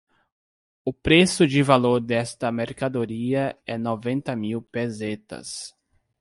/meʁ.ka.doˈɾi.ɐ/